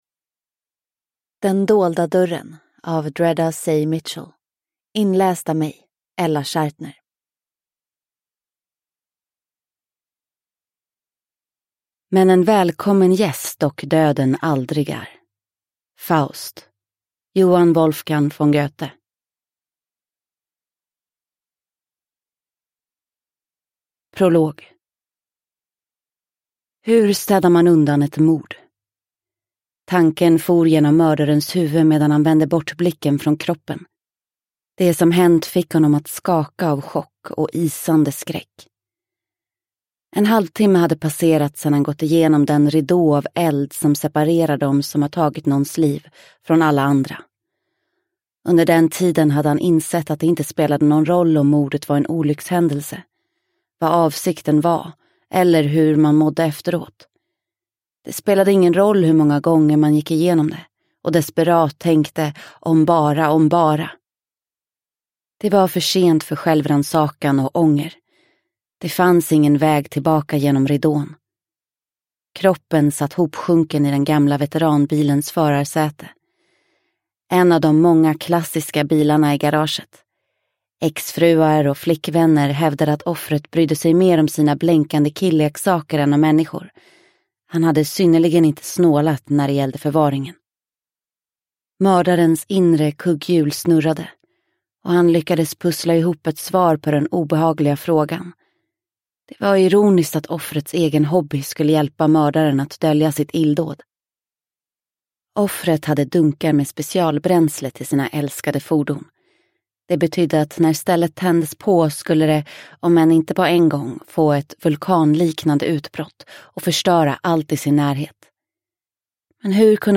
Den dolda dörren – Ljudbok – Laddas ner